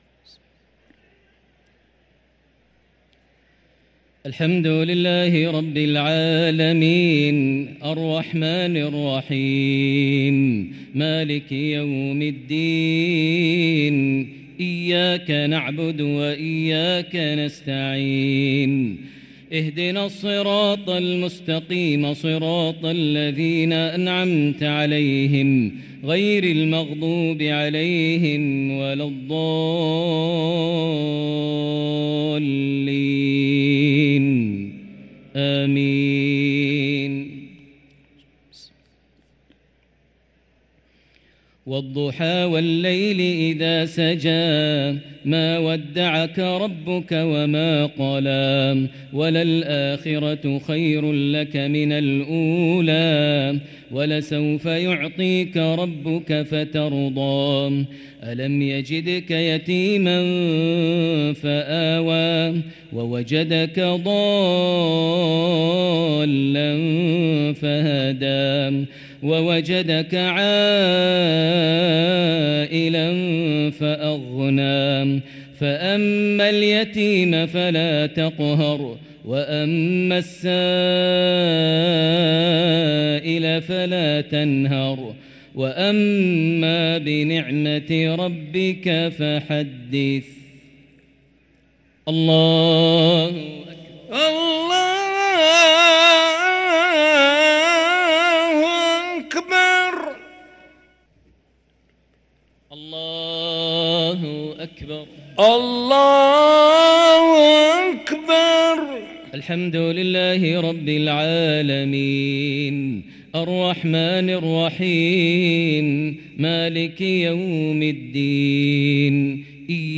صلاة العشاء للقارئ ماهر المعيقلي 8 رمضان 1444 هـ
تِلَاوَات الْحَرَمَيْن .